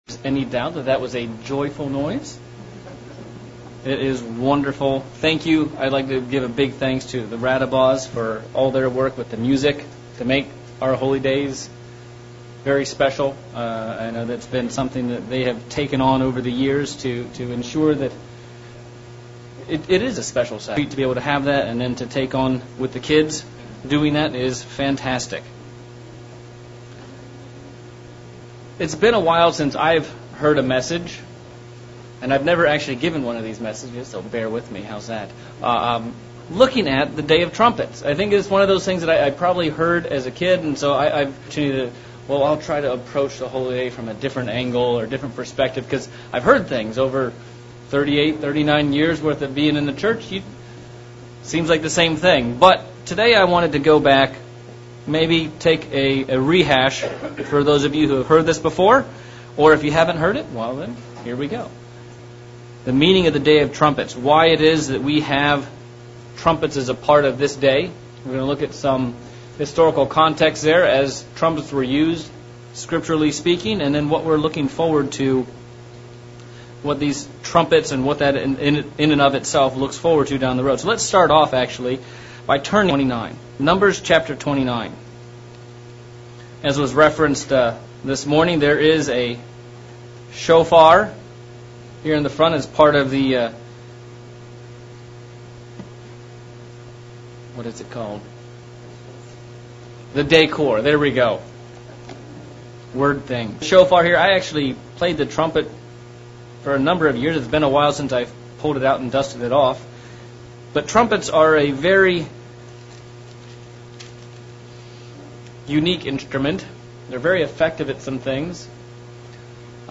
Trumpets PM sermon on the meaning of this special Holy Day and what it means for our future